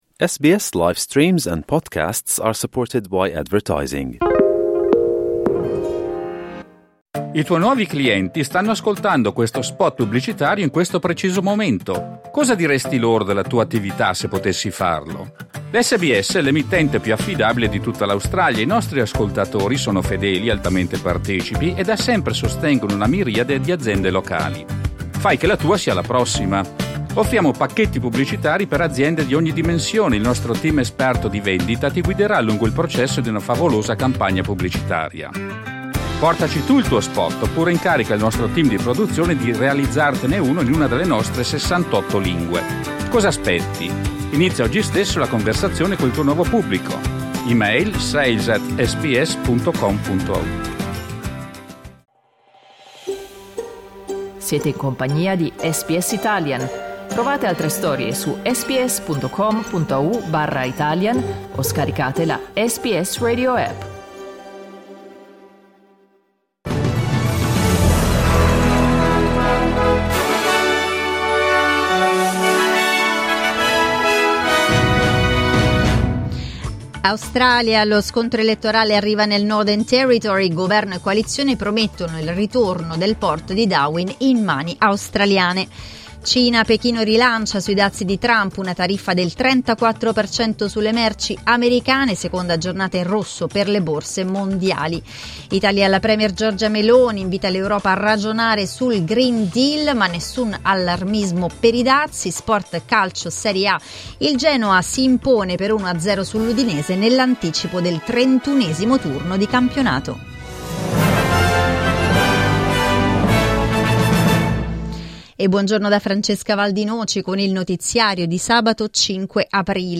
Giornale radio sabato 5 aprile 2025
Il notiziario di SBS in italiano.